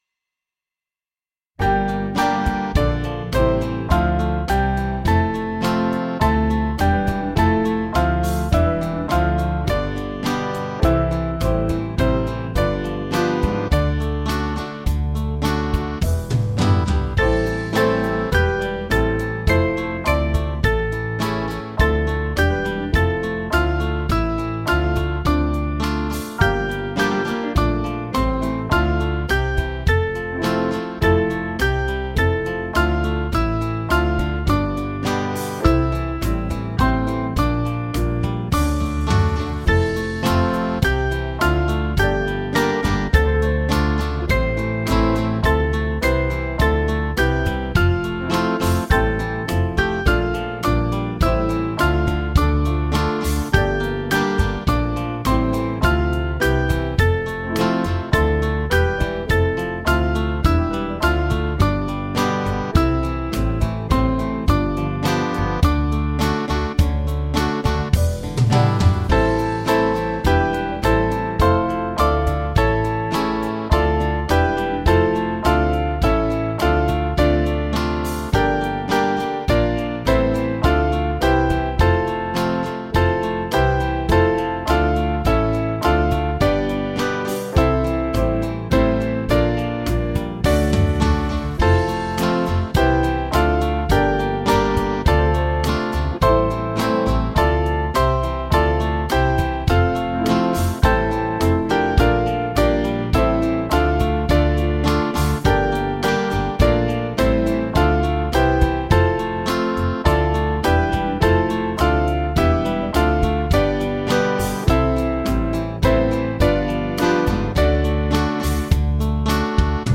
Small Band
(CM)   3/Dm
with chorus   478.4kb